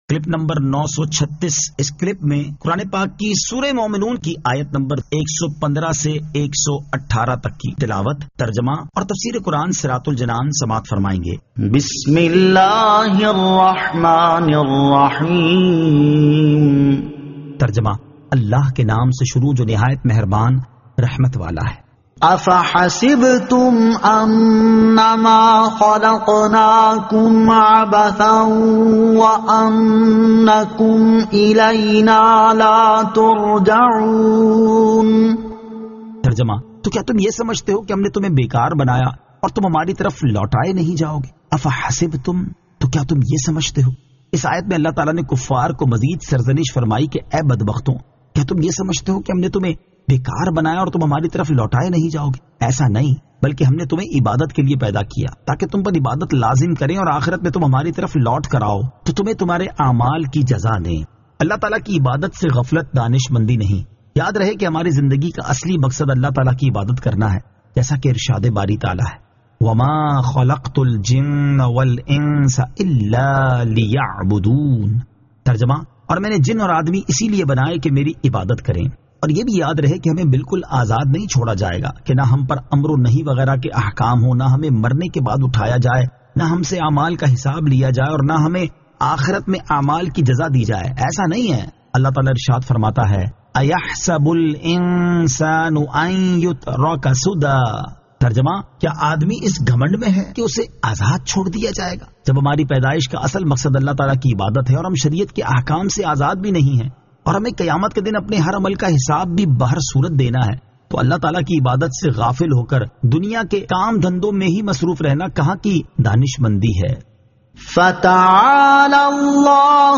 Surah Al-Mu'minun 115 To 118 Tilawat , Tarjama , Tafseer